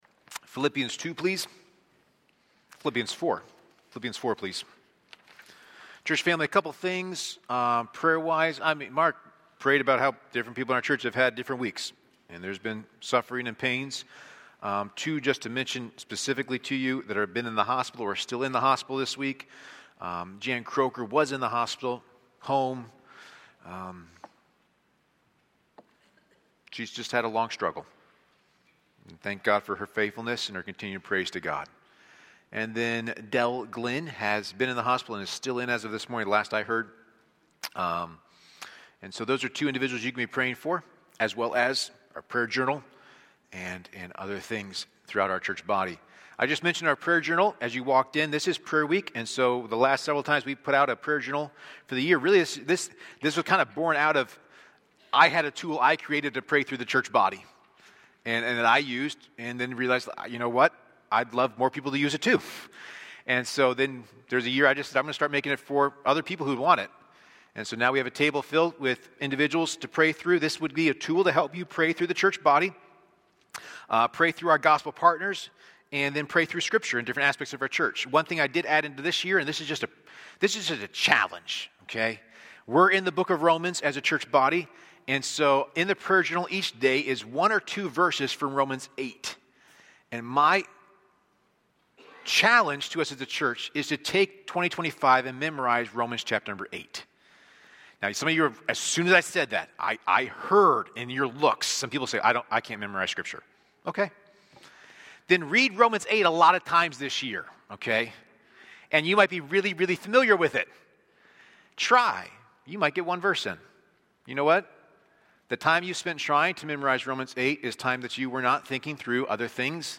A sermon from the series "Prayer."